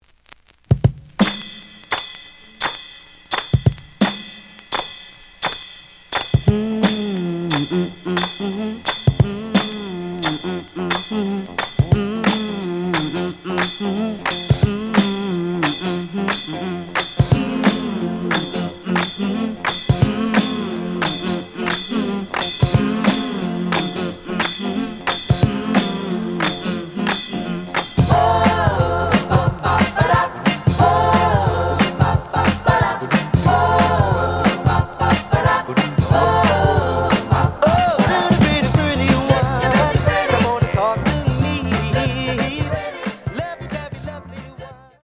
試聴は実際のレコードから録音しています。
The audio sample is recorded from the actual item.
●Genre: Soul, 70's Soul
Slight edge warp.